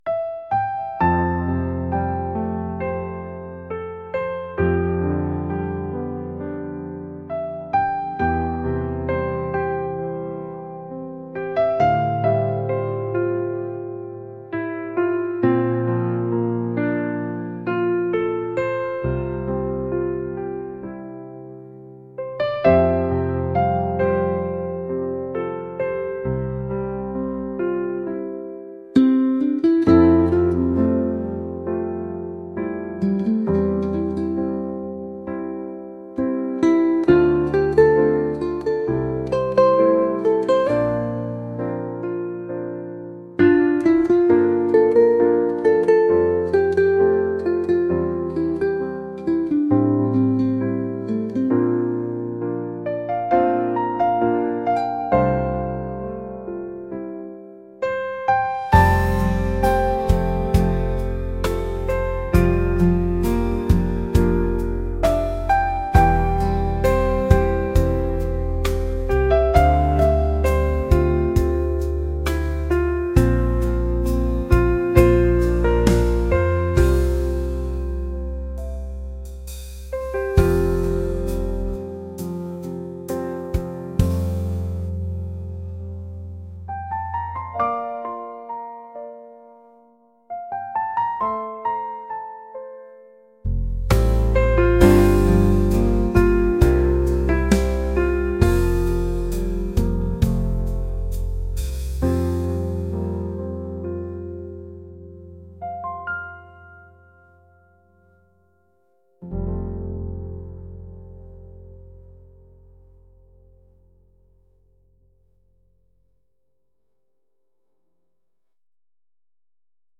serene-piano.mp3